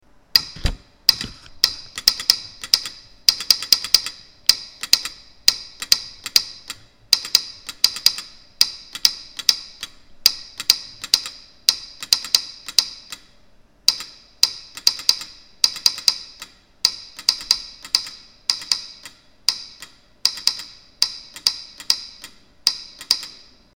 The information on this page will show you how we connected a telegraph sounder (circa 1920) to a computer and use it to "play" Morse Code in our classroom.
The sounder has such a unique sound, we wanted to hear it play real Morse Code.
A dot is set to .1 second.
The space between letters is 3 times a dot and the space between words is 7 times a dot.
This is a recording of the sounder.
sounder.mp3